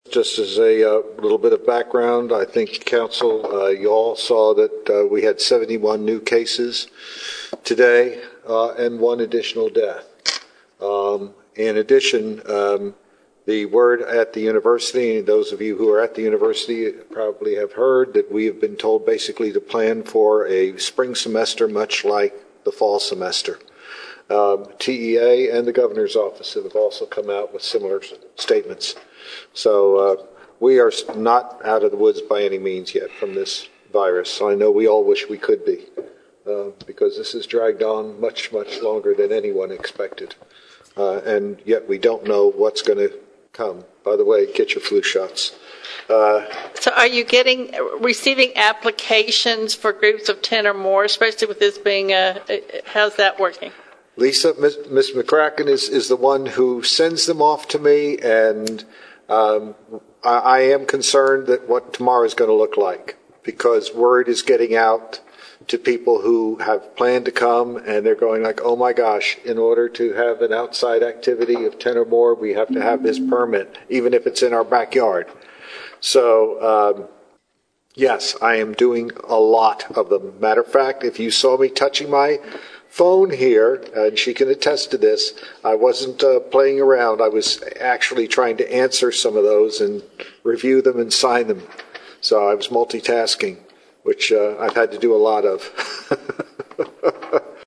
Click below for comments from College Station mayor Karl Mooney during the September 26, 2020 city council meeting.